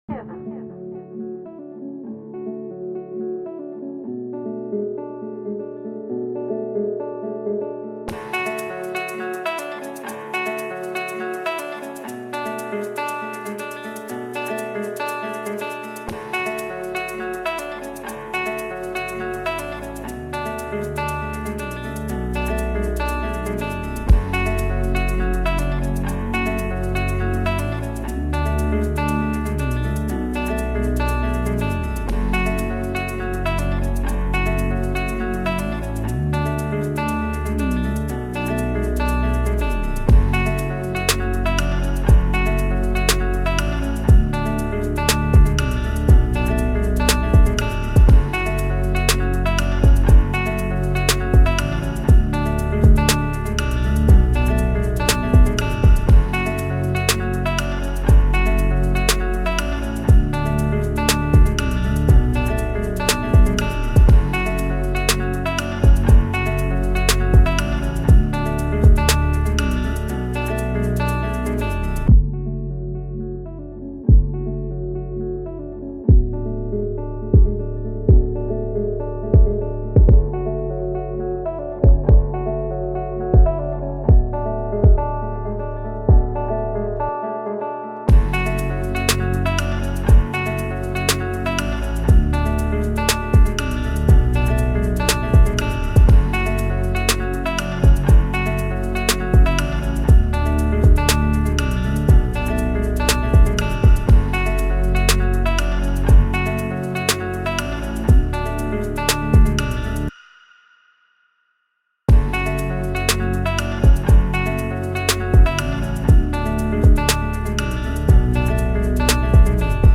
LBxiRJ7CZ4N_pnl-au-dd-instrumental.mp3